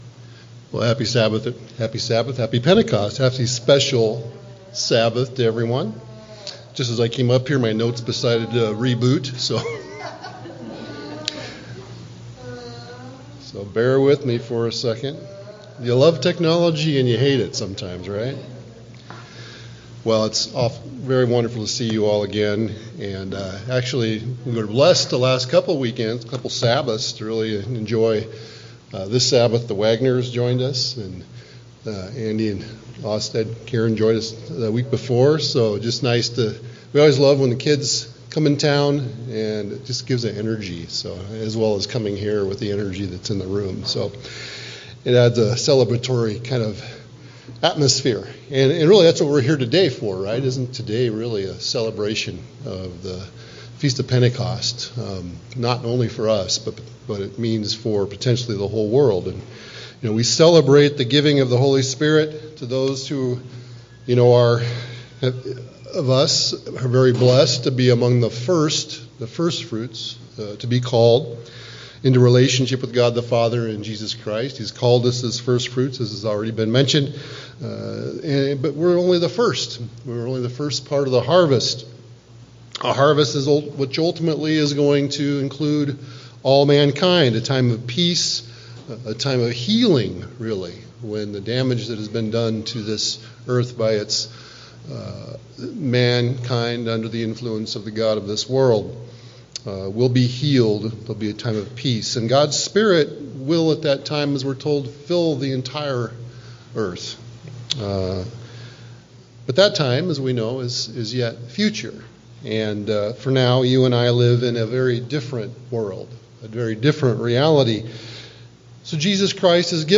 This Pentecost message explores how the Holy Spirit functions not only to strengthen us individually, but also to protect and preserve the spiritual health of the Body of Christ—the Church.